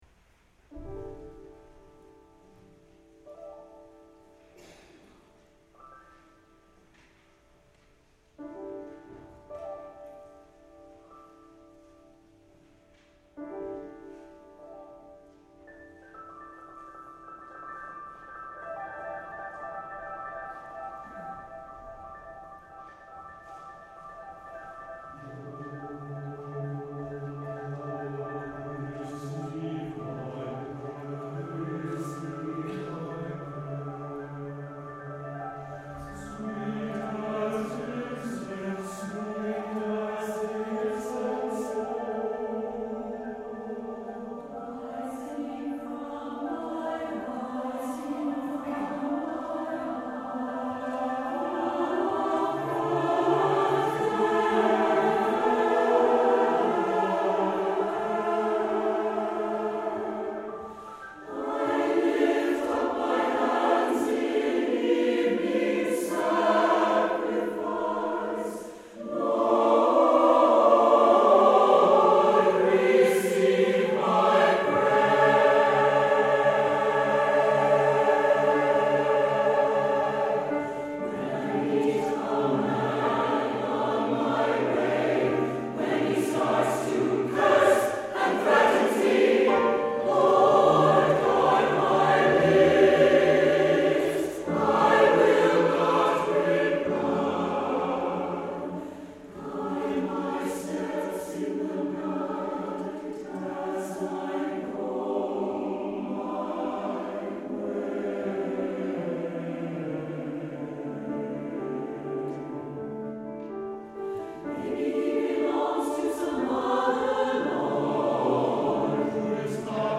for SATB Chorus and Piano (2006)